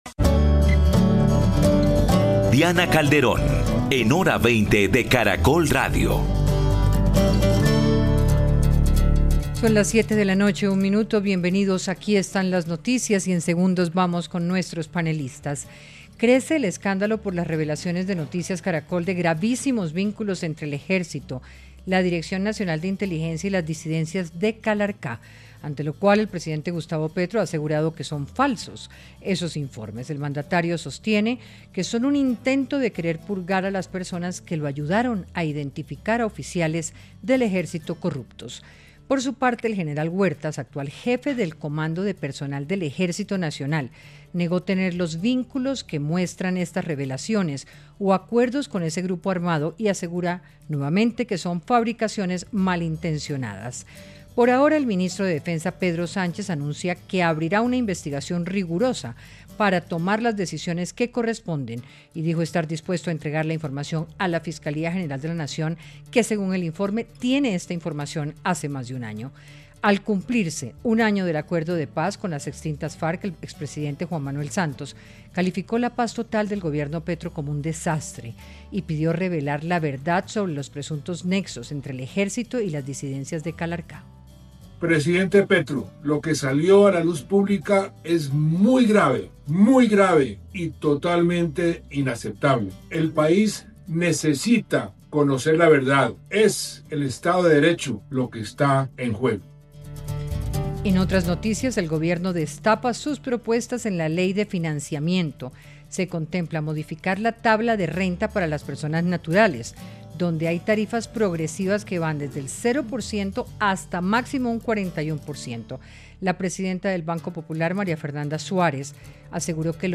Panelistas analizaron la información revelada por Noticias Caracol sobre un entramado en el que un funcionario del DNI y un general del Ejército tendrían estrecha relación con las disidencias de “Calarcá”.